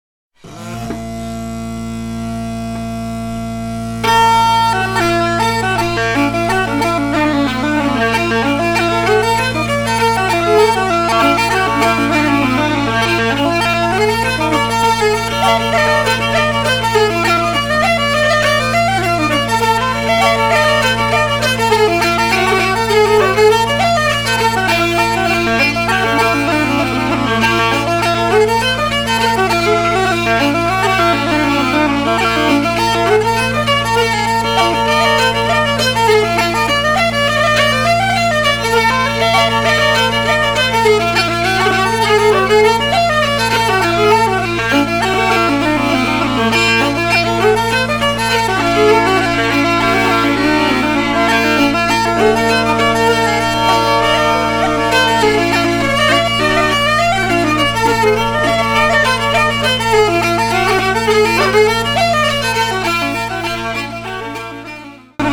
fiddle, hardanger fiddle, whistle